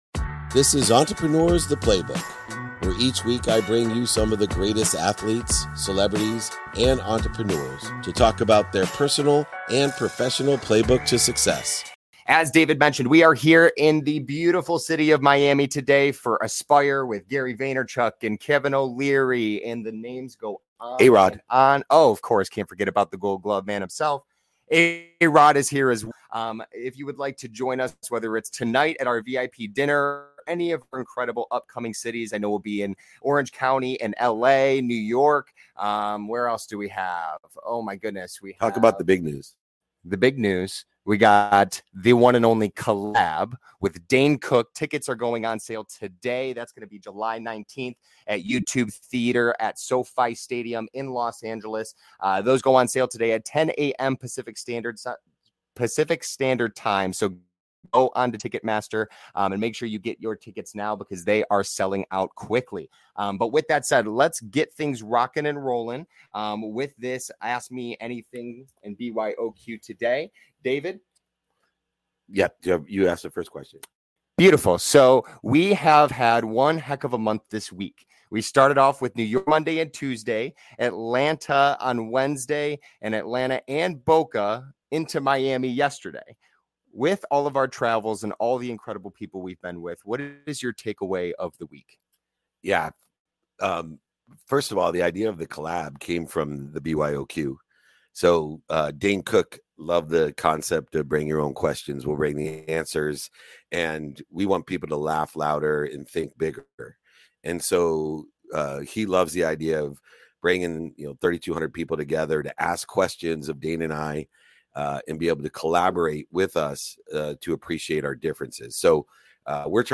In today's episode, I take you through a vibrant BYOQ (Bring Your Own Questions) session, where I engage with a diverse audience, answering a spectrum of insightful queries. We explore the power of community building versus merely gathering an audience, the profound impact of consistently addressing fear, and the importance of identifying and committing to non-negotiables regardless of life's unexpected turns.